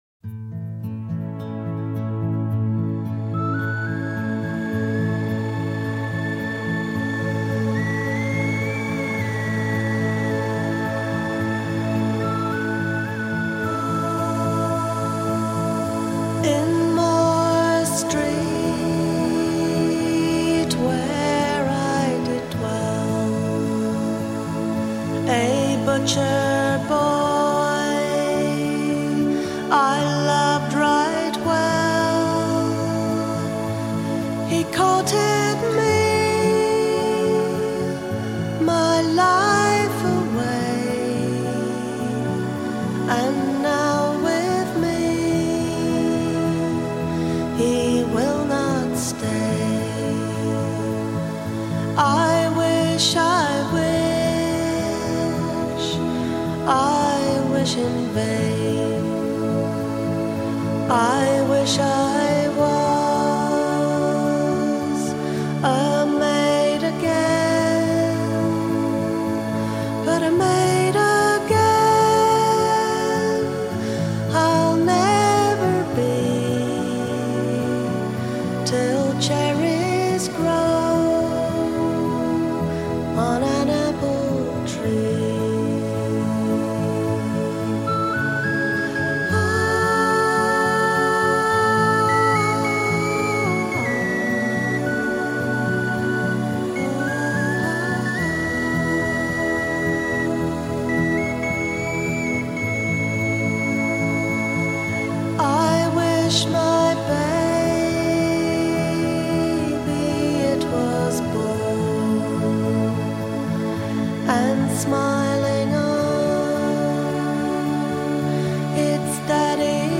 penny whistle